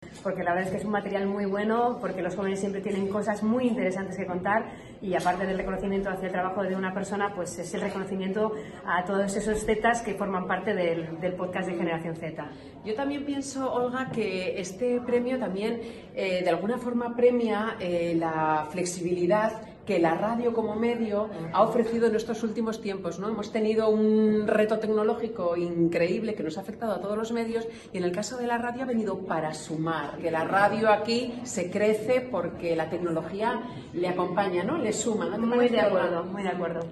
El auditorio Mitxelena, del Bizkaia Aretoa en Bilbao, sirvió de escenario para que la Organización agradeciera y homenajeara a toda la sociedad vasca por la solidaria actitud con la que día a día le apoya a través de la compra de sus productos de juego responsable.